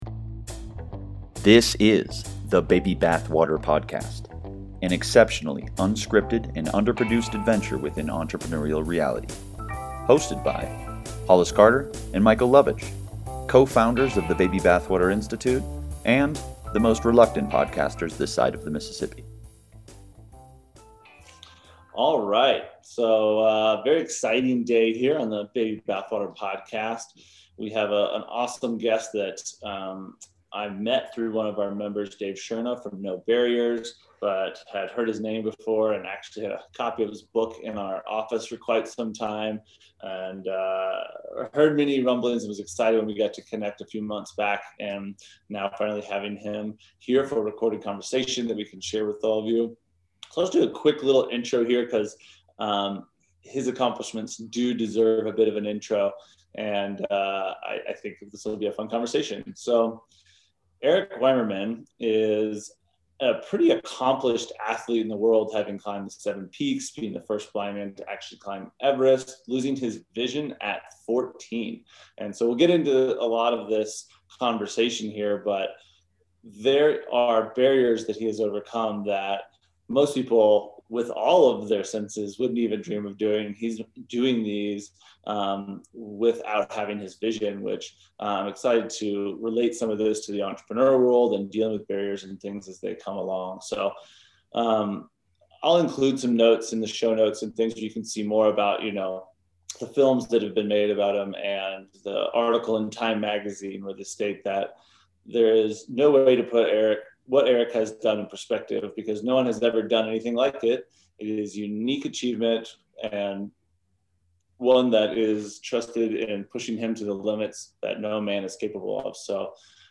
A Conversation with a World-Renowned Adventurer, Speaker, Author, and Teacher… Who Happens to be Blind